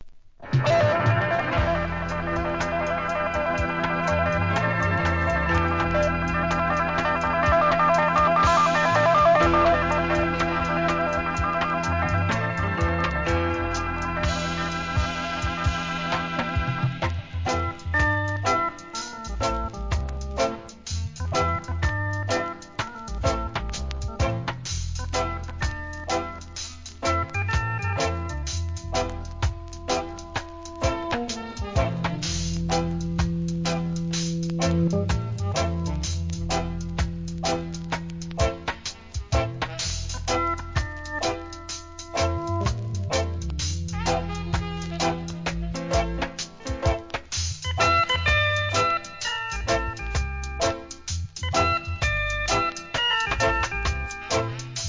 REGGAE
オルガン・インスト・レゲエ名盤!!